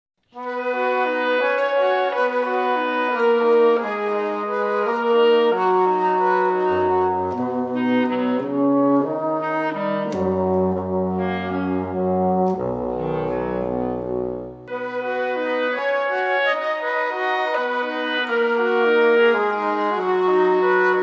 per quintetto di Fiati